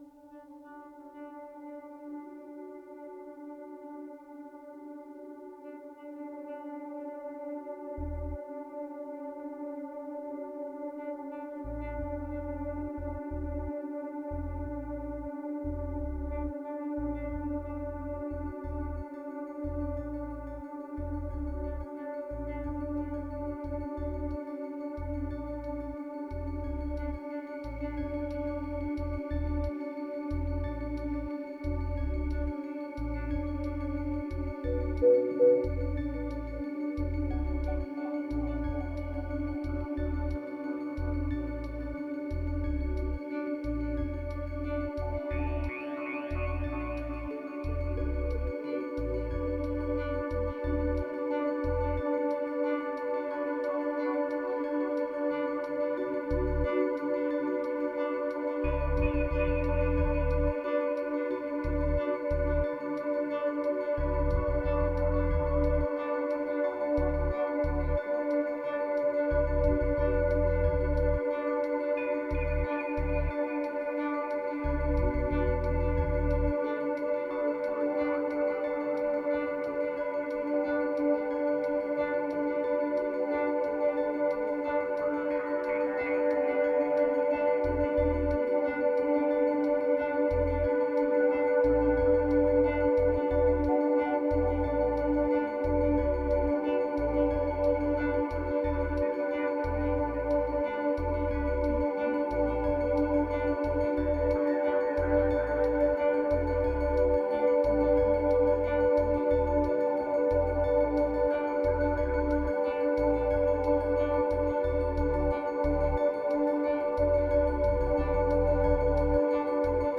2521📈 - 84%🤔 - 90BPM🔊 - 2017-06-03📅 - 802🌟